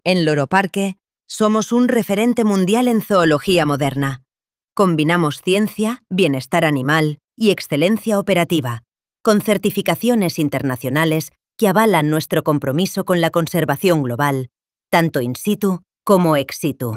Muestra de voces con IA
Voces femeninas
Suave y con matices